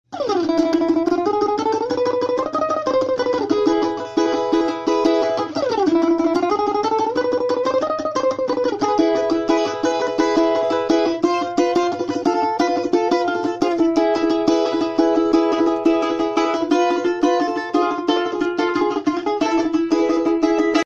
Звук гри на балалайці